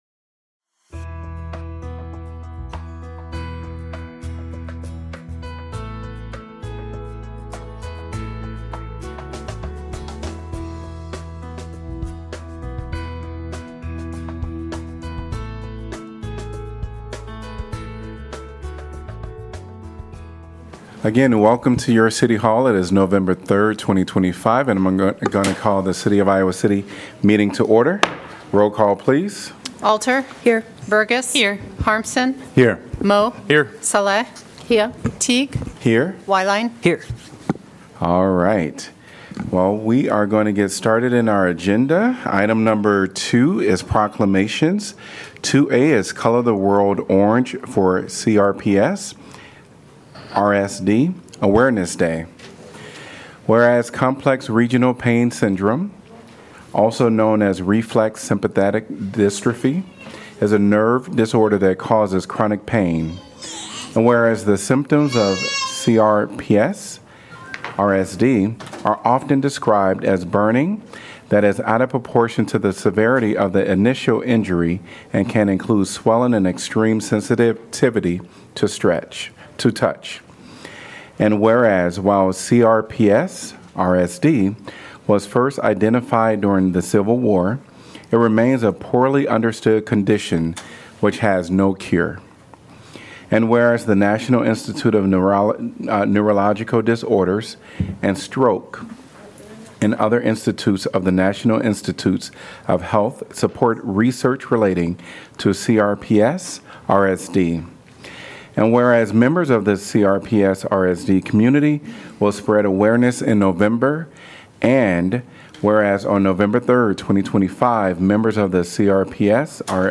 Gavel-to-gavel coverage of the Iowa City City Council meeting, generally scheduled for the first and third Tuesday of each month.